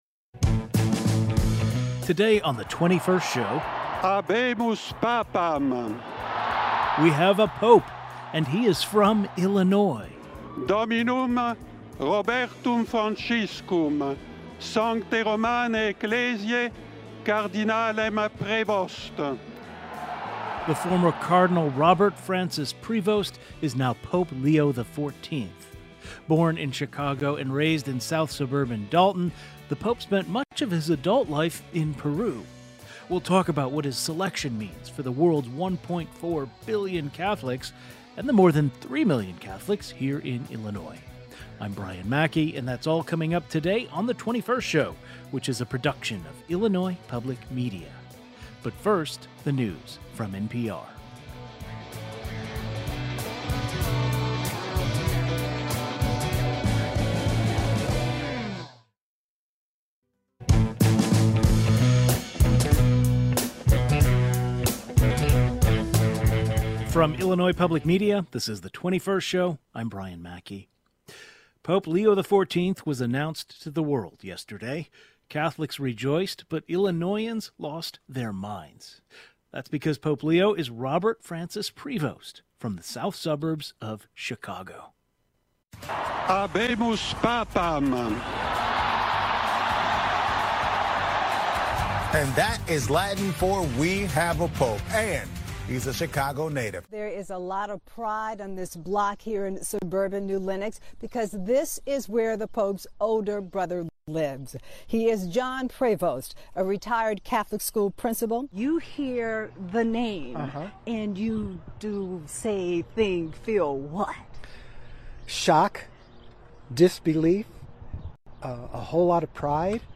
On today's program, we discuss what his selection means for the 21st state and also hear from members of Illinois' Catholic community, who personally know Pope Leo and worked with him.